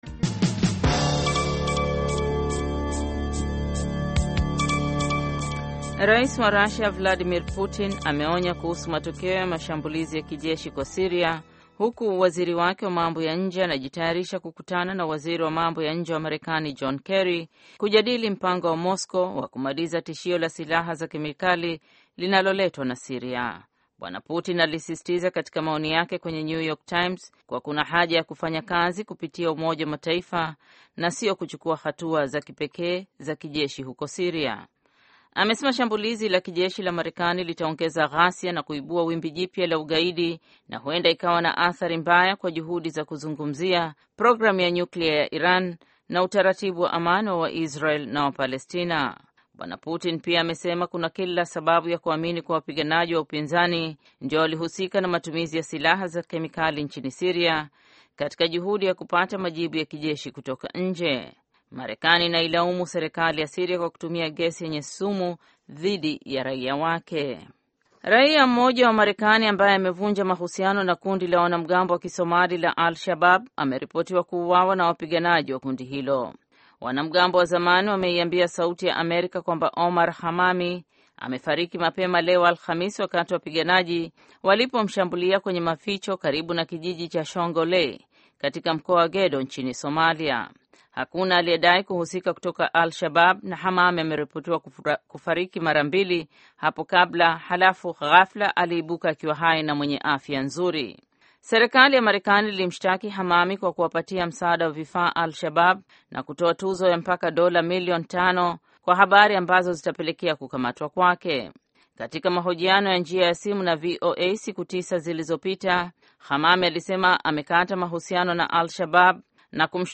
Taarifa ya Habari VOA Swahili - 6:55